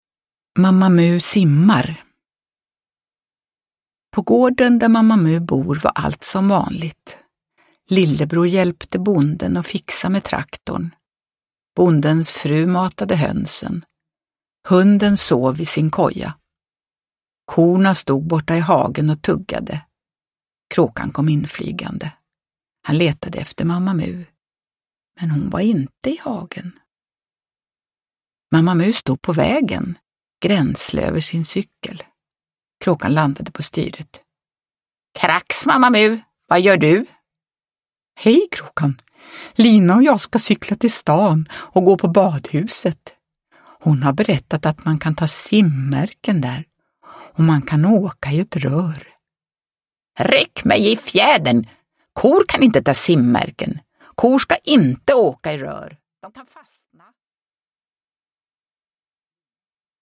Mamma Mu simmar – Ljudbok – Laddas ner
Uppläsare: Jujja Wieslander